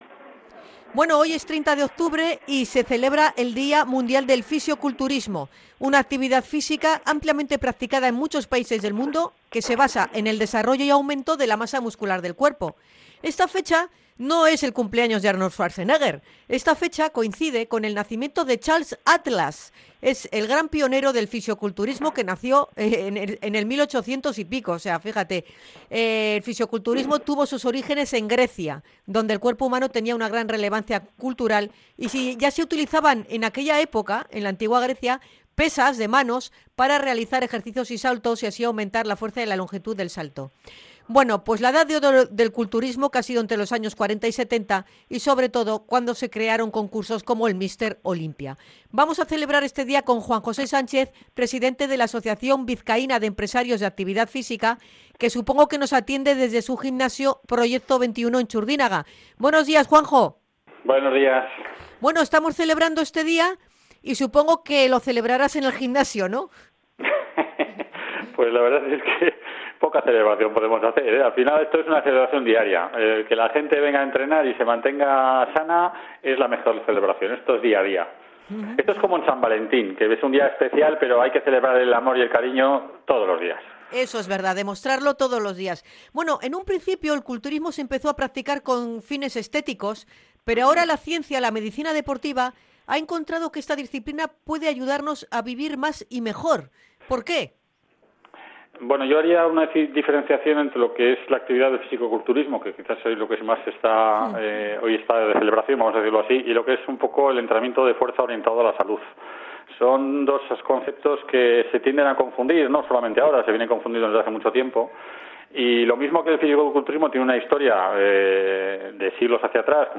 INT.-DIA-MUNDIAL-DEL-FISIOCULTURISMO.mp3